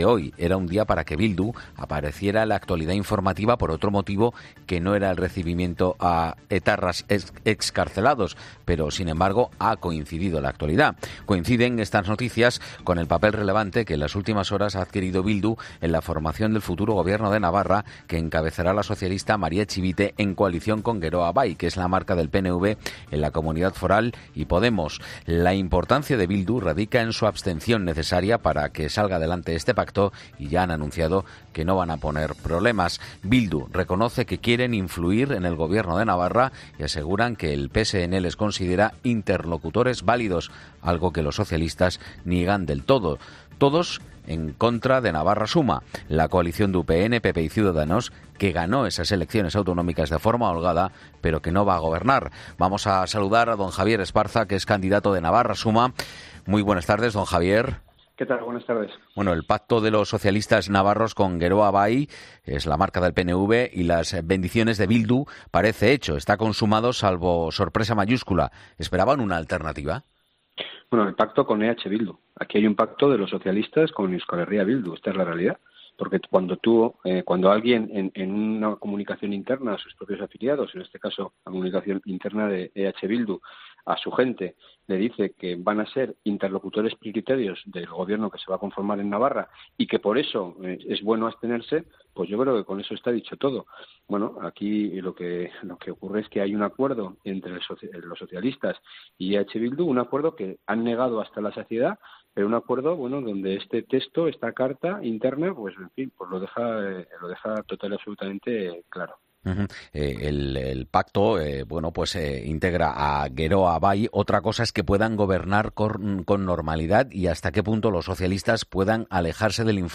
"El PSN está en manos de Bildu", afirma en 'Mediodía COPE',  Javier Esparza, candidato de Navarra Suma que acusa a Pedro Sánchez y al ministro de Fomento en funciones y Secretario de Organización del Partido Socialista, José Luis Ábalos de mentir, "aquí tenemos un Presidente a nivel nacional, el señor Sánchez que siempre ha firmado que no iba a pactar con los amigos y los asesinos de ETA y la realidad es que hay un documento que dice que EH Bildu son interlocutores prioritarios, hay un acuerdo y el Presidente del Gobierno  falta la verdad.